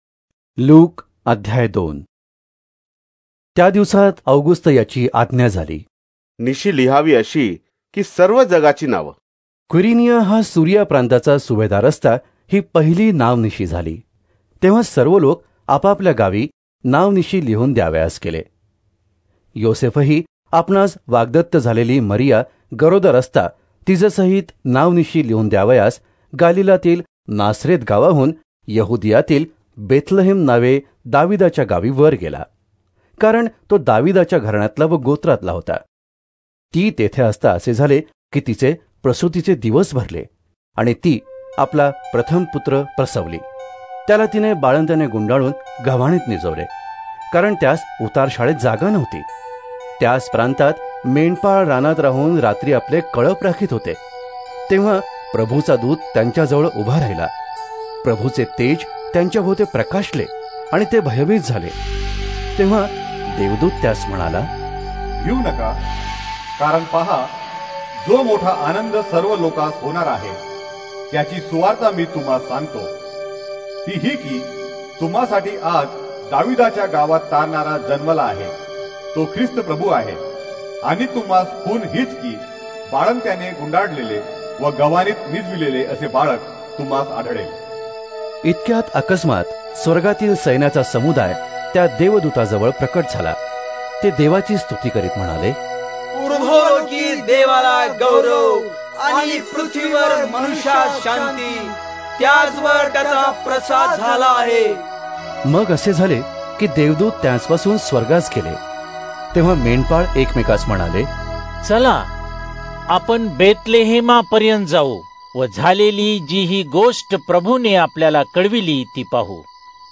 Marathi Audio Drama Bible New Testament - United Evangelical Christian Fellowship(UECF), New Jersey - Popular Christian Website Telugu Hindi Tamil Malayalam Indian Christian audio Songs and daily bible devotions